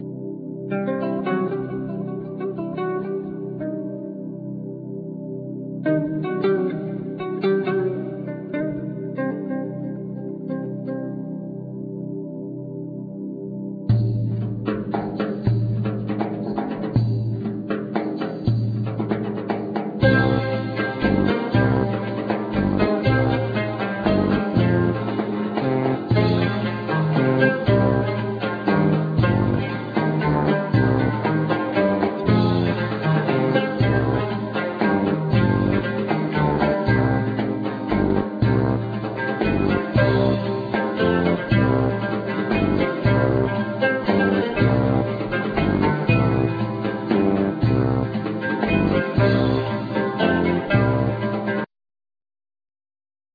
Ney
Oud,Vocals
Kalimba
Bass,Synthsizer,Percussions,Mandola,12 string guitar
Violin